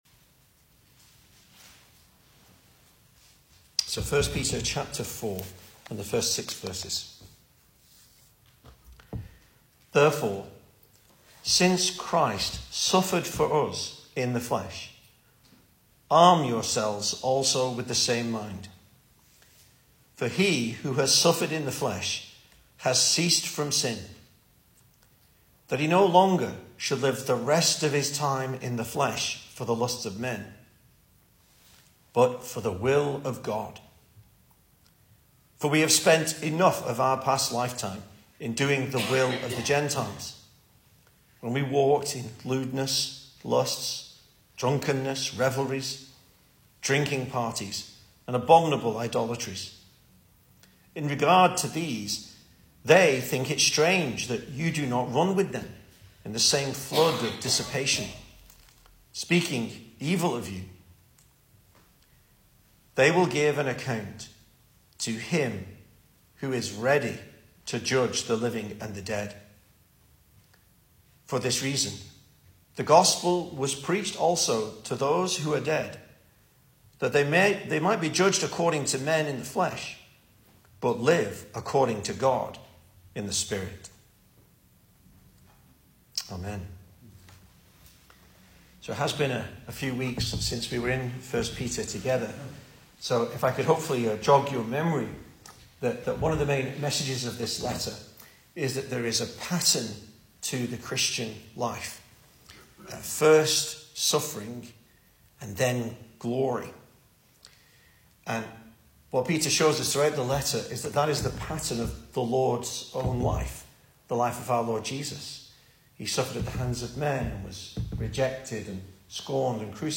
2024 Service Type: Weekday Evening Speaker